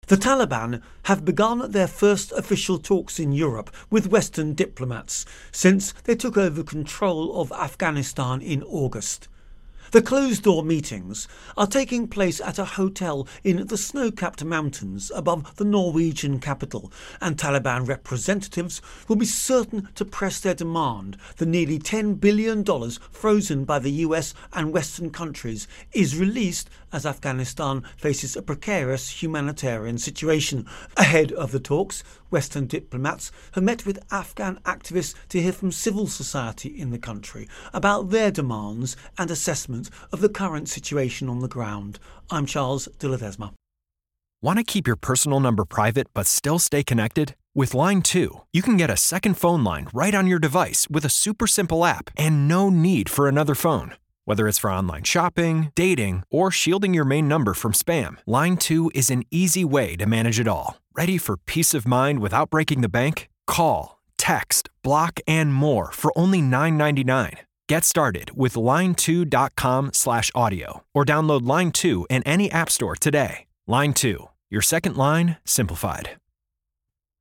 Intro and Voicer on Norway-Afghanistan-Taliban